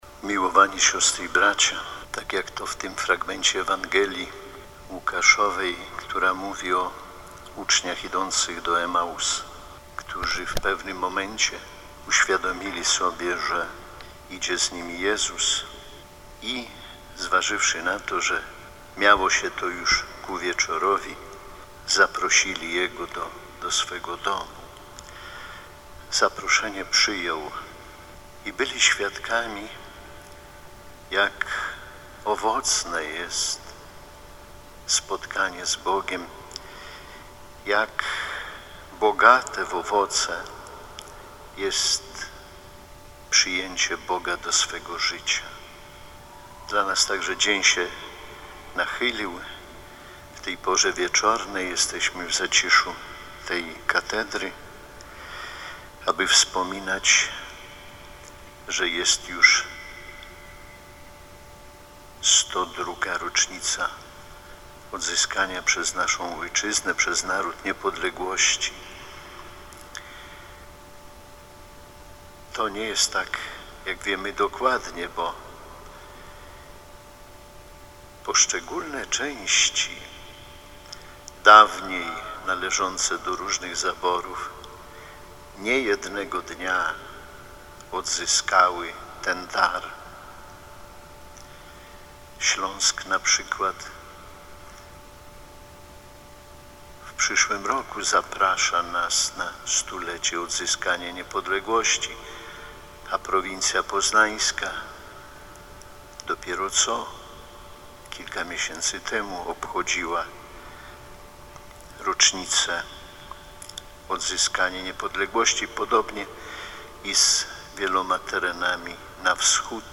Z okazji Narodowego Święta Niepodległości, w katedrze warszawsko-praskiej odprawiono mszę świętą w intencji Ojczyzny. Eucharystii przewodniczył bp Romuald Kamiński.
cala-homilia-bp-Kaminski.mp3